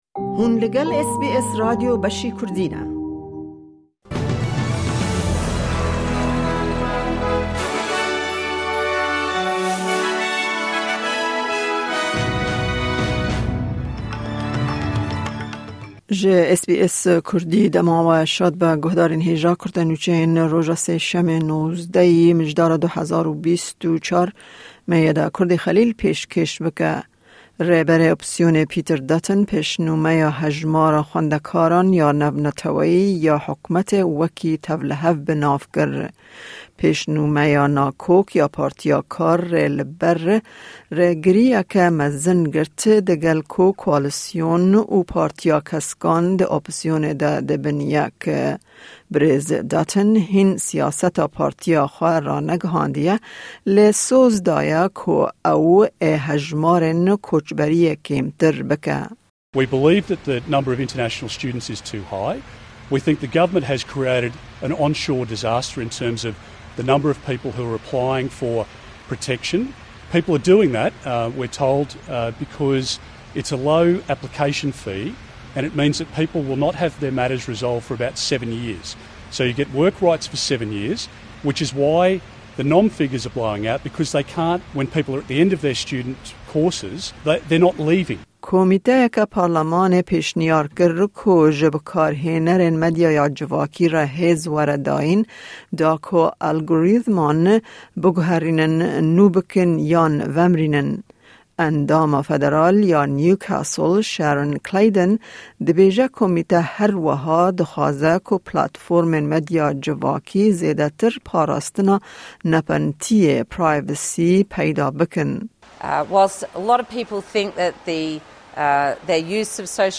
Kurte Nûçeyên roja Sêşemê 19î Mijdara 2024